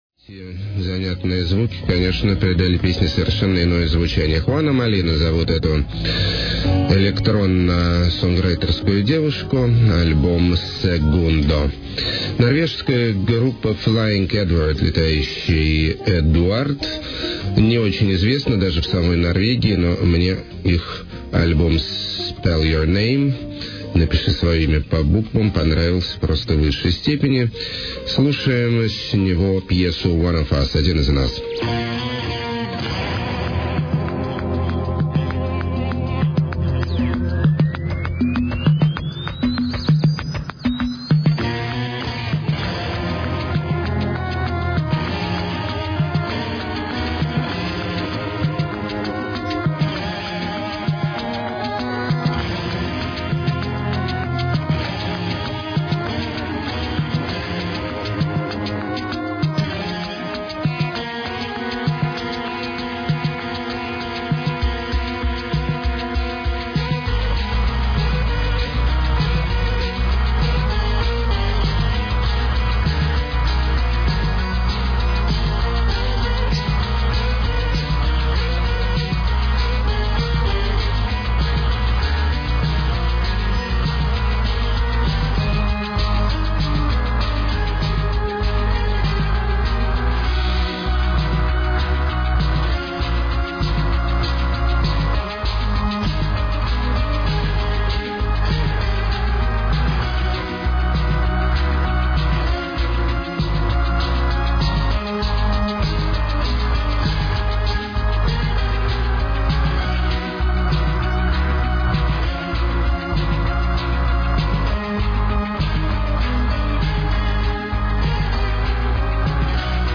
latin boleros under electronic treatment
shamelessly bourgeoise jazz
southern soft techno
college rock with post-rockish sound
gothic pop
trippy guitar rock
nu be bop
downtempo pioneers display conceptual ambitions